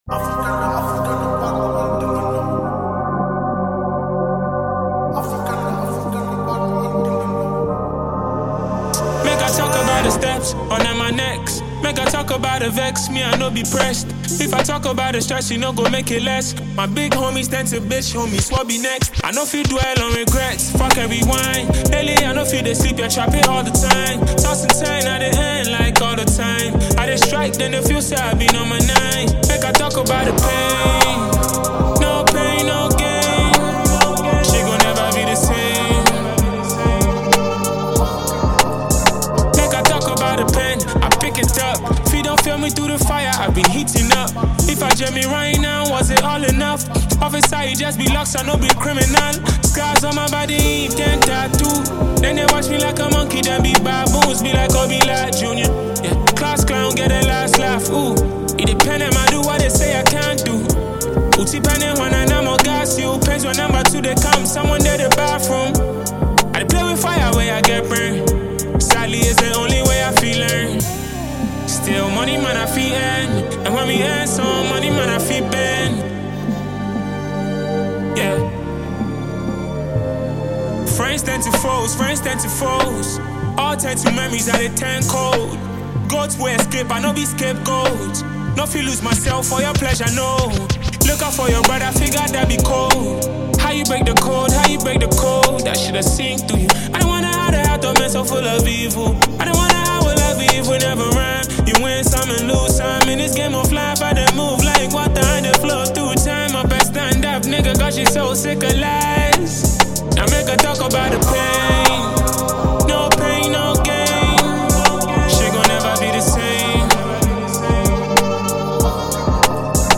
hardcore rap record